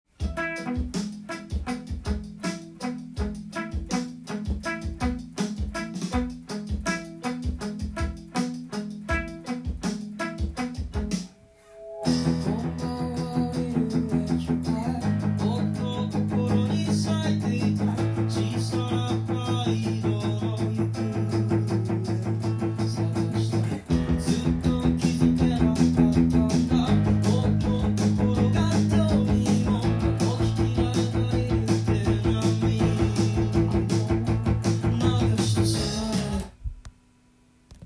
音源に合わせて譜面通り弾いてみました
バイオリンのピチカートが聞こえ始める０：１５秒～
すべてダウンピッキングで弾ける譜面になっています。
ギターパートはひたすらブリッジミュートをしながら
パワーコードをダウンピッキングで弾いていきます。